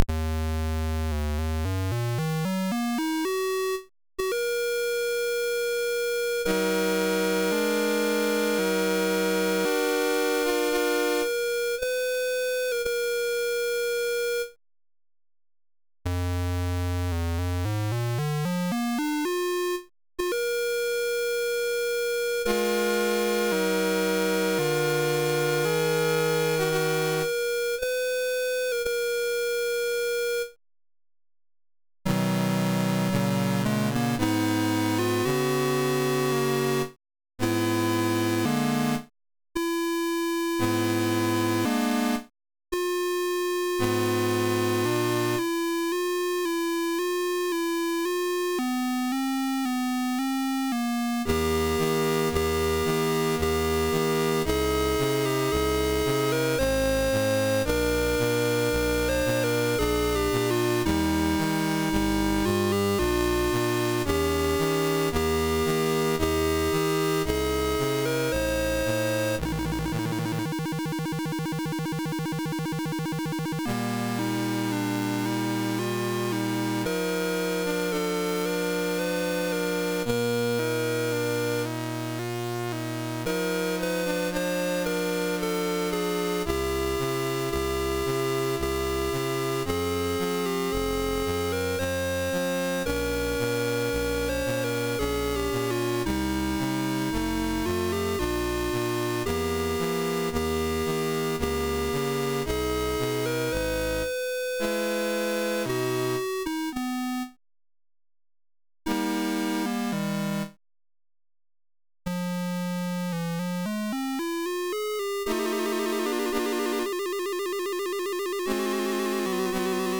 home *** CD-ROM | disk | FTP | other *** search / Loadstar 96 / 096.d81 / dawn-wm.tell.mus ( .mp3 ) < prev next > Commodore SID Music File | 2022-08-26 | 1KB | 1 channel | 44,100 sample rate | 3 minutes